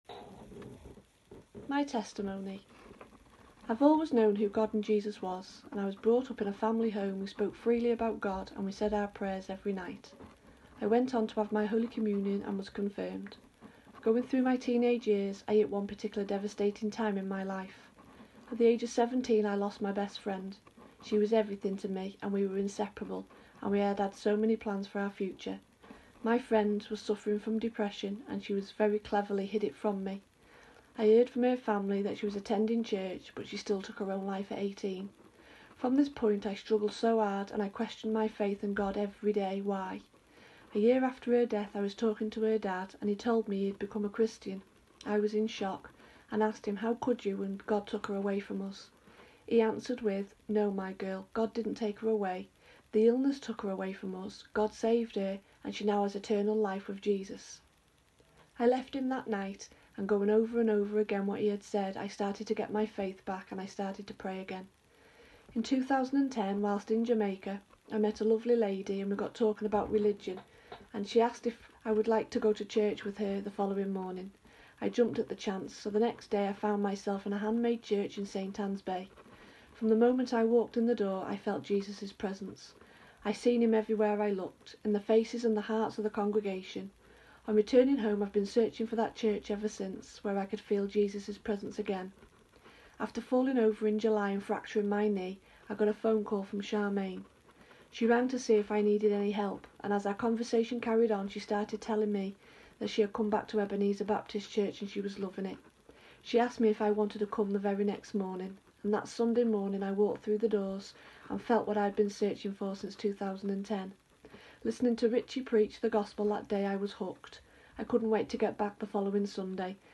she shared the night of her baptism: